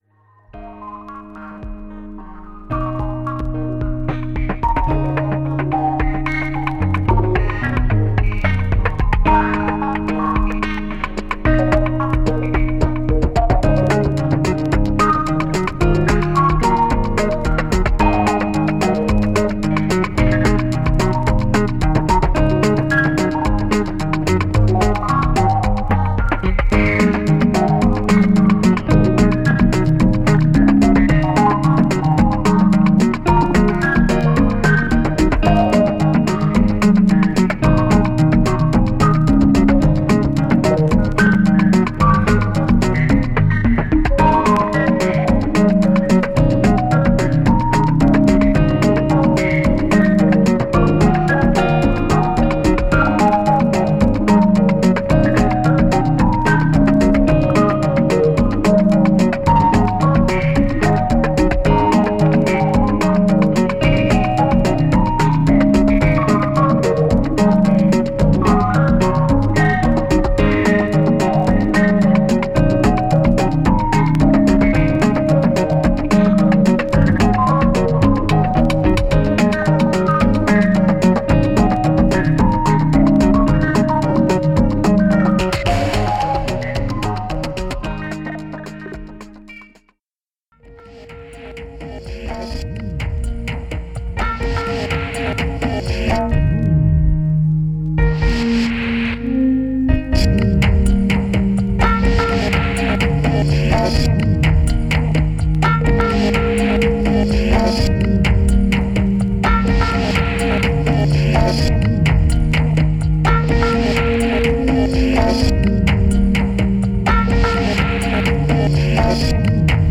ELECTRONIC
どこか切ないサウンドが心地良いです！！！